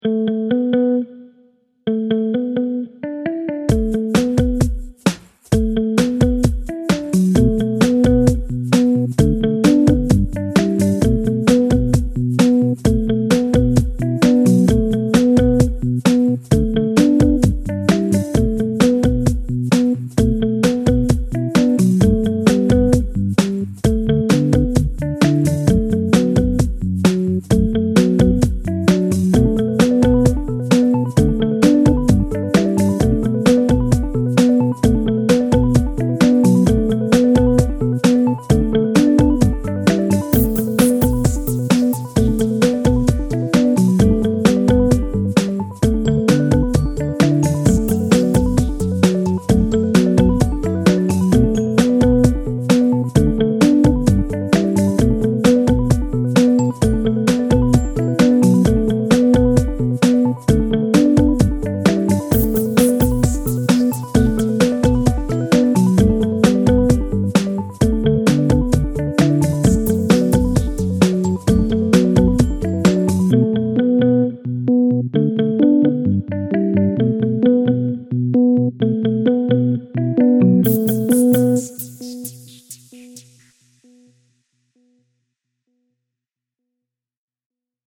Moving